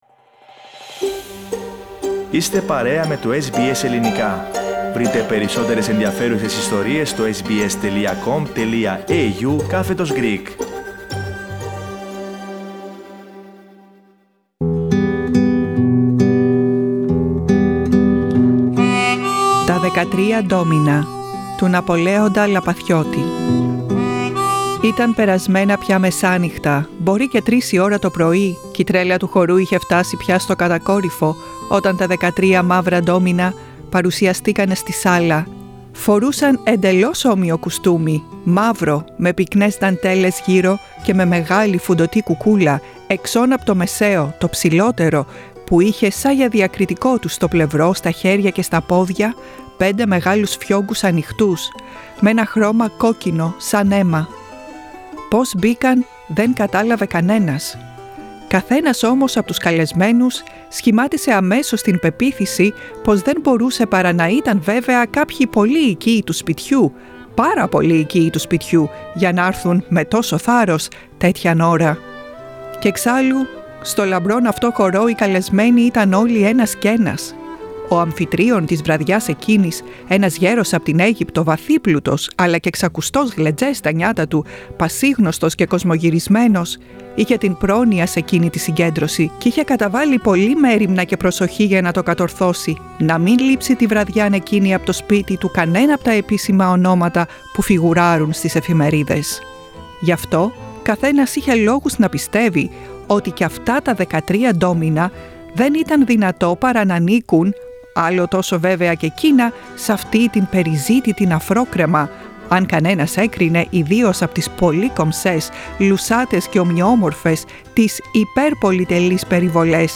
A short story by Napoleon Lapathiotis.